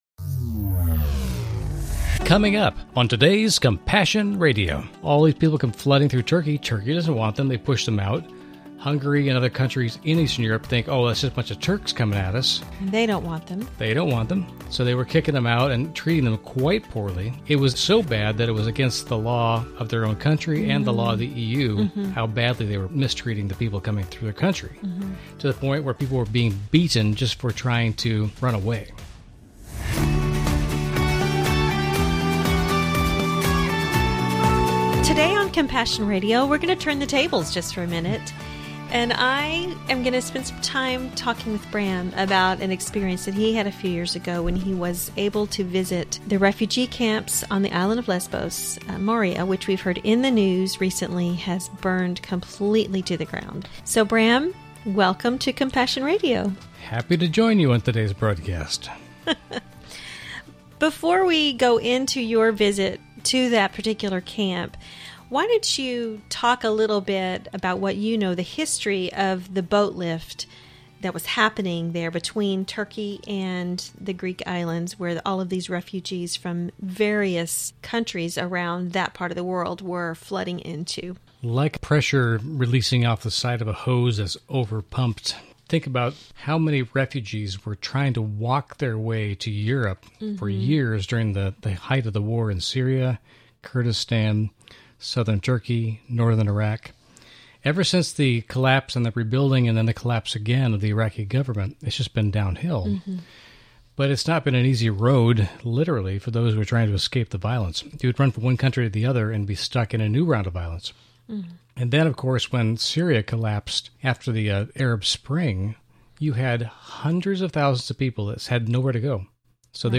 Genre: Christian News Teaching & Talk.